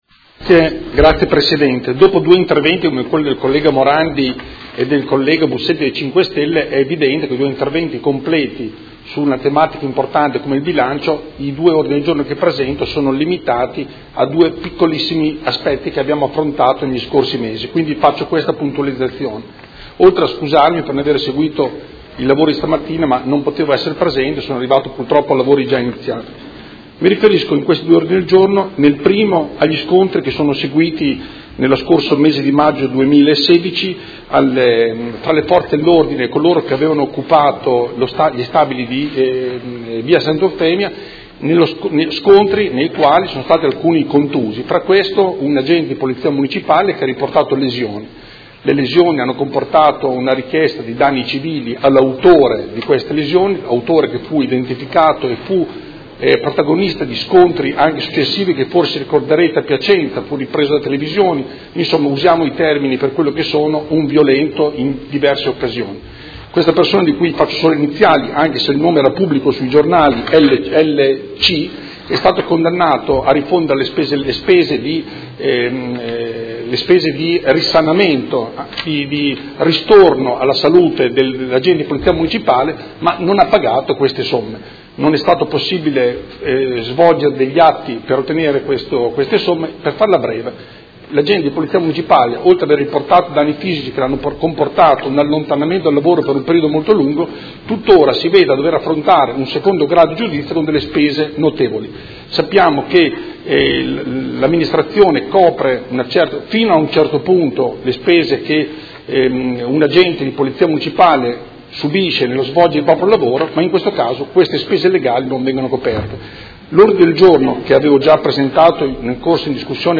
Seduta del 20/12/2018. Presenta Ordine del Giorno Prot. Gen. 212240